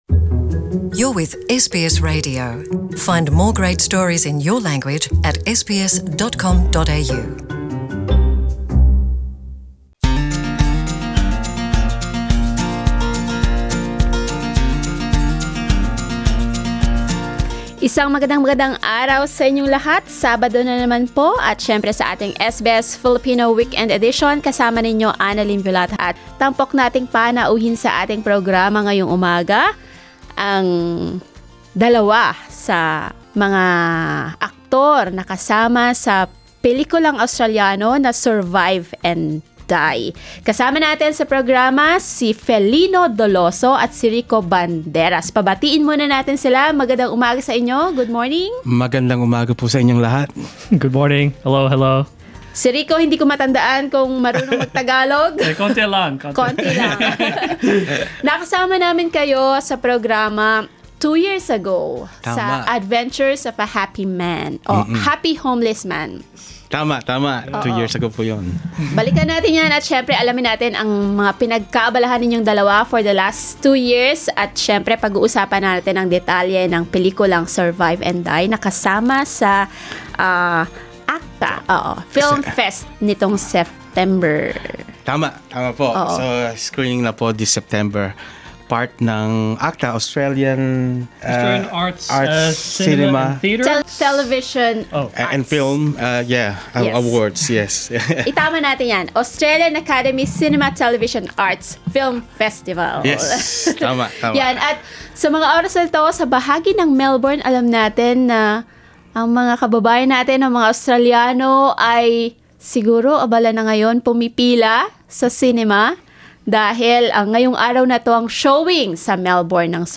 Screening at the Australian Academy Cinema Television Arts (AACTA) Film Festival this September, 'Survive or Die' features for the first time African and Filipino actors in its lead roles.